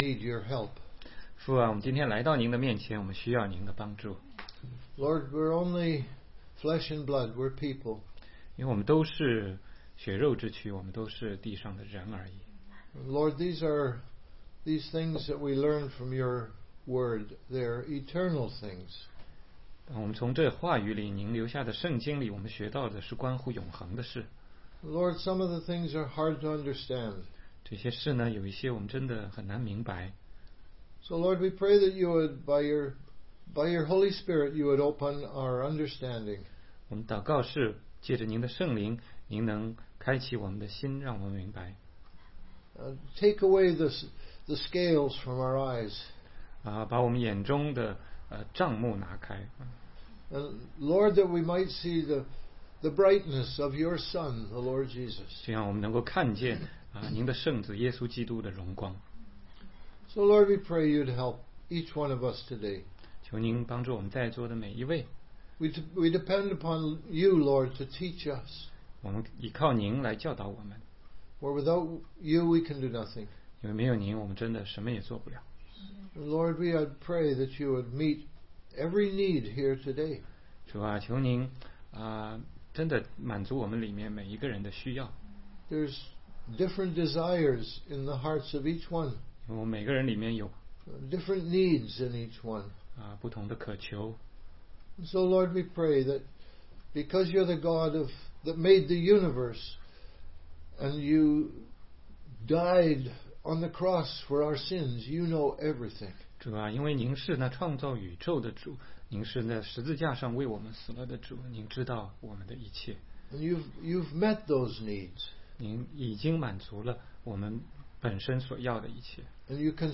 16街讲道录音 - 约翰福音7章6-7节